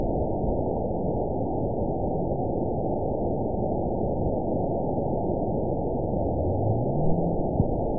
event 920317 date 03/15/24 time 18:58:39 GMT (1 year, 1 month ago) score 9.44 location TSS-AB05 detected by nrw target species NRW annotations +NRW Spectrogram: Frequency (kHz) vs. Time (s) audio not available .wav